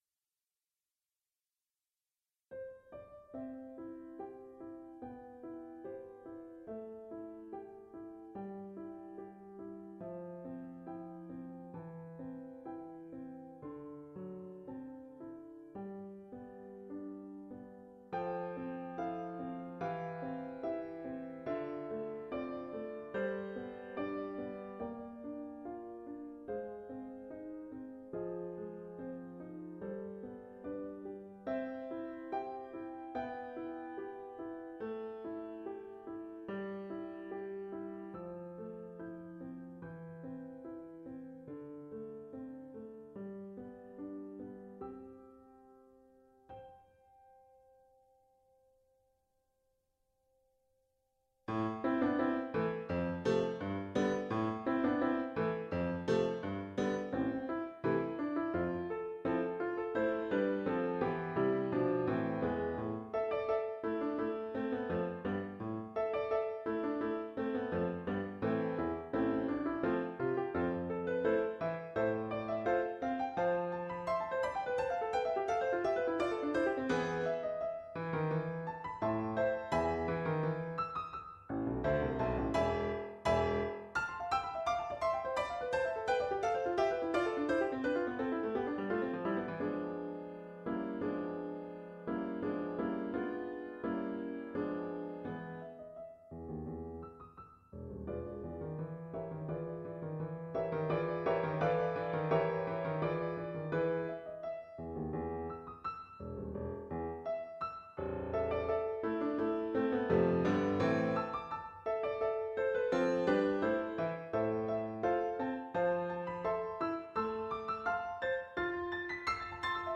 Psalm Offering 6 Opus 2 for piano
The music has elements of what I think is musical Americana. It has neo-classical/early romantic period elements, along with the music that is often associated as uniquely American.
The Classic period’s Alberti bass in the left hand, to the arpeggios of the Romantic period joined with a melody I consider Americana.